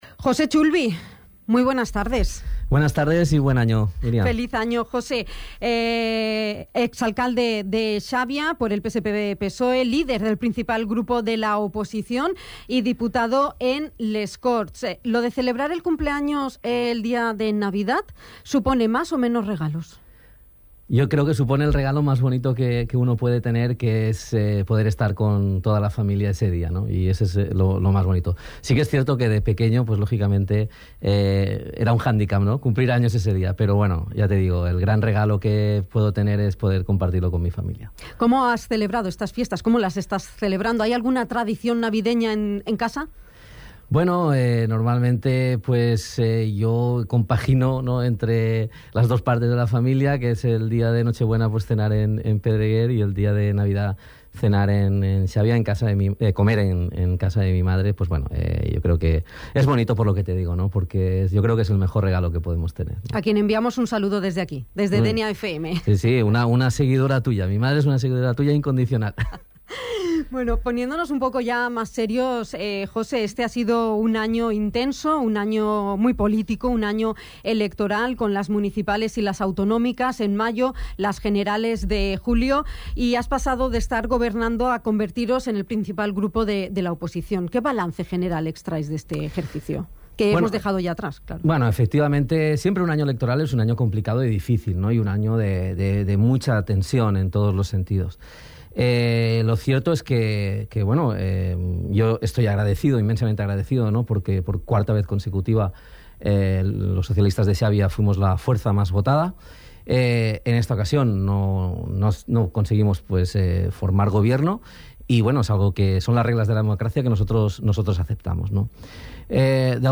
El ex alcalde de Xabia, líder del principal grupo en la oposición en ese Ayuntamiento y diputado en Les Corts Valencianes por el PSPV-PSOE, José Chulvi ha visitado la redacción informativa de Dénia FM, para realizar balance del ejercicio 2023.
Entrevista-Jose-Chulvi-2.mp3